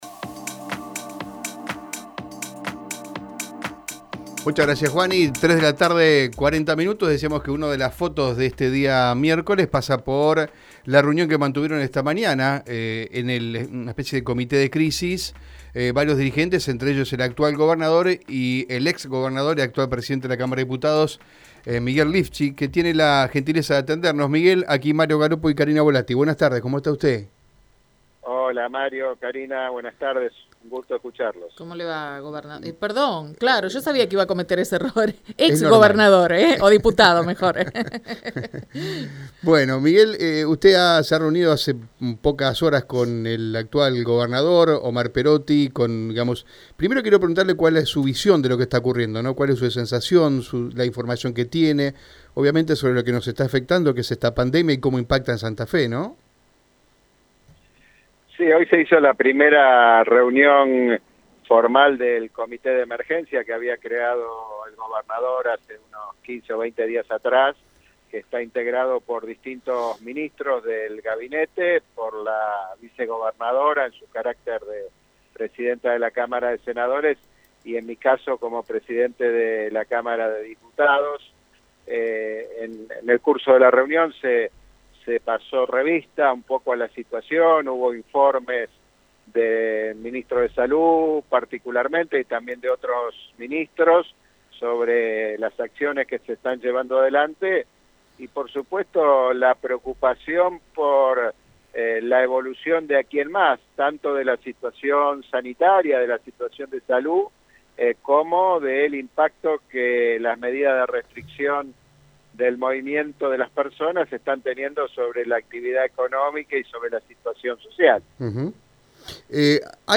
En dialogo con Radio EME, el presidente de la Cámara de Diputados, Miguel Lifschitz, habló sobre de la primera reunión del Comité de Crisis convocada por el gobernador de la provincia de Santa Fe Omar Perotti.